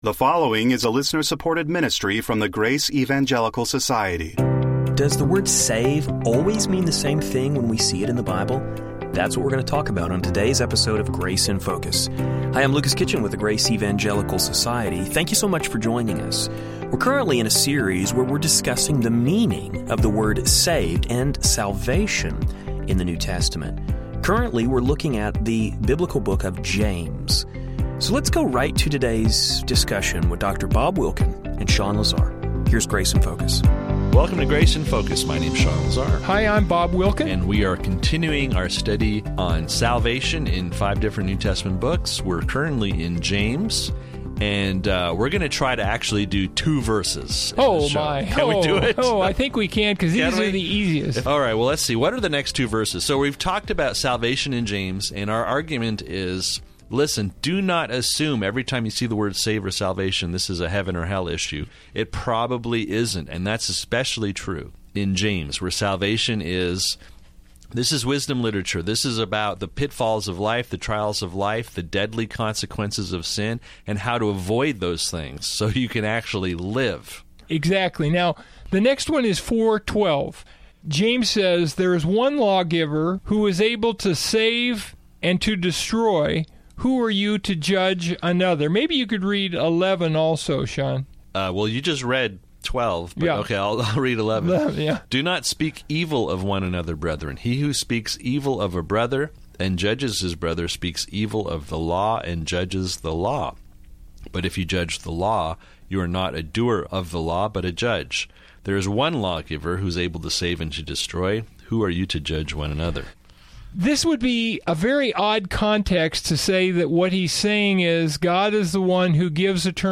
They are currently looking at the epistle of James. Today, we will hear the guys address a few passages in James including 4:12 and 5:15.